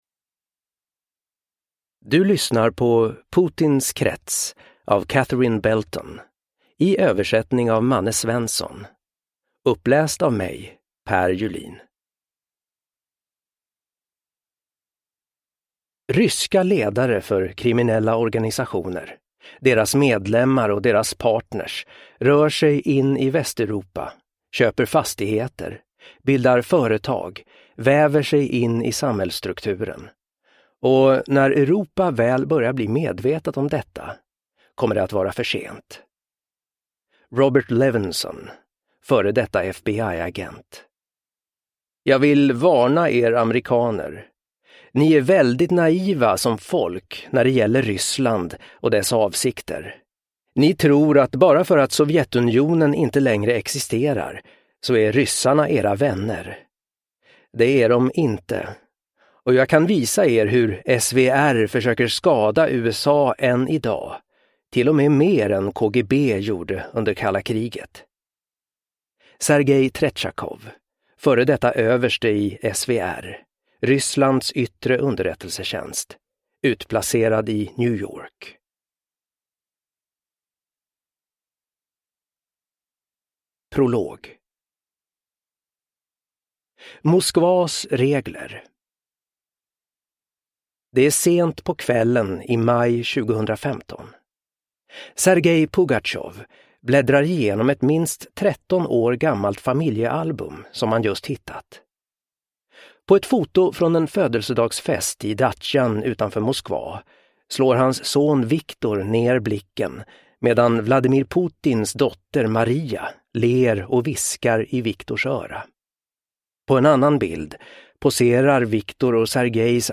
Putins krets : maktkampen om det moderna Ryssland – Ljudbok